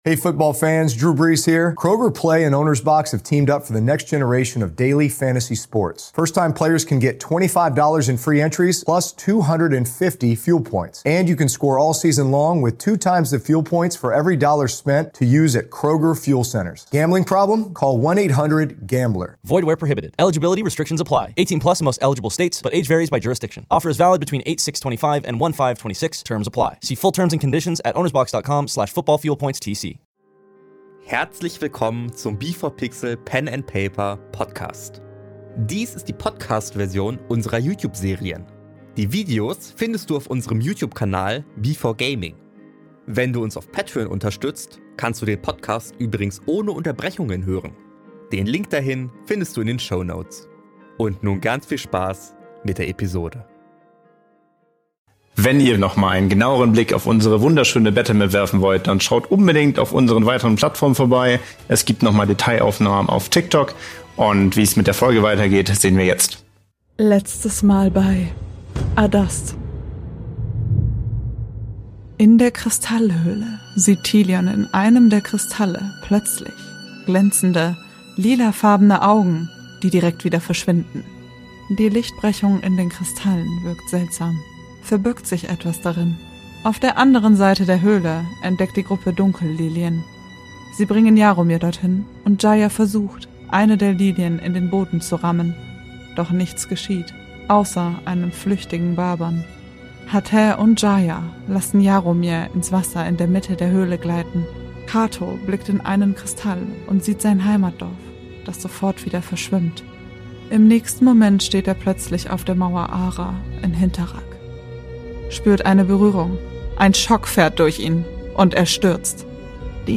Dies hier ist die Podcast-Version mit Unterbrechungen.